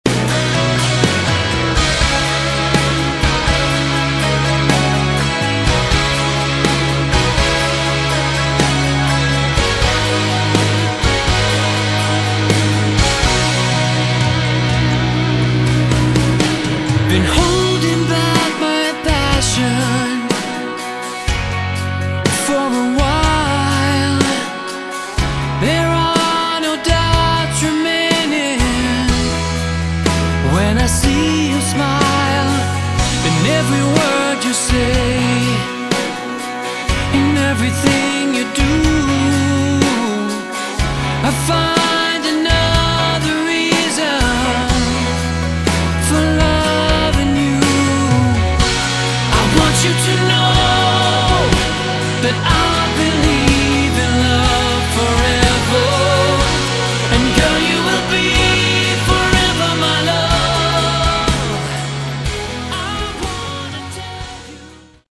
Category: Hard Rock
vocals, lead & Rythm guitars
keyboards, acoustic & Clean guitars
drums, keyboards
bass/Fretless bass